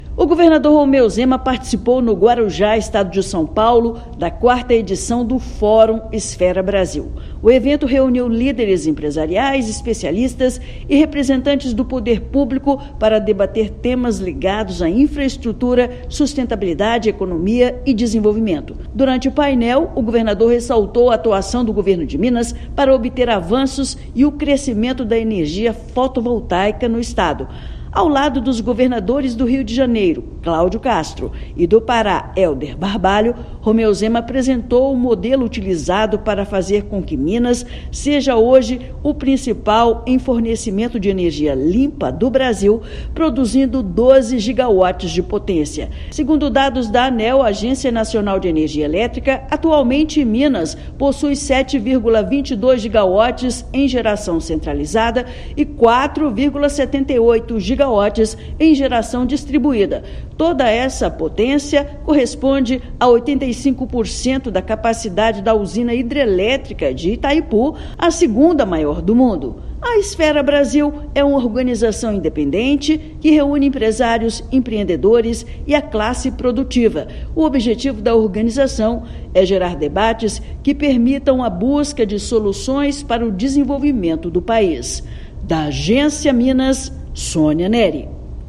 Governador participou do evento da Esfera Brasil, no Guarujá (SP), e apontou medidas para transformar o estado no maior produtor de energia solar do Brasil. Ouça matéria de rádio.